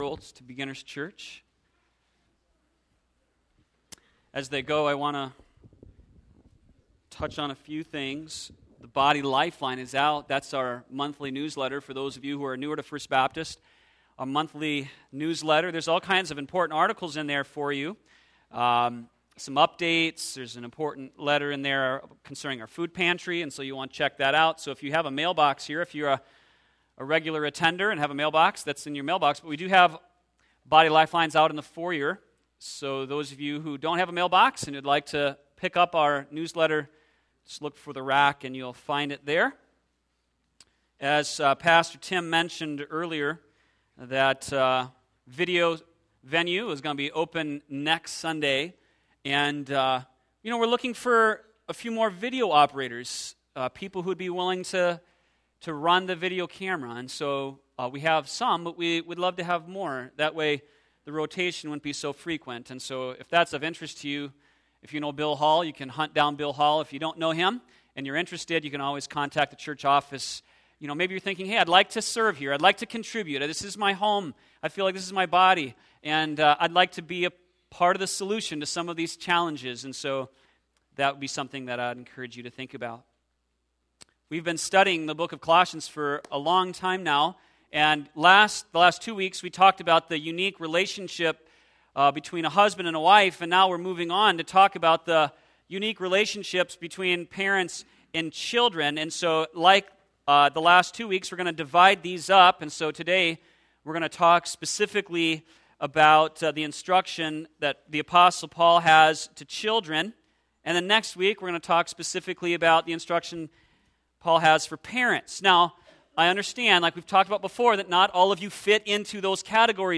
sermon10613.mp3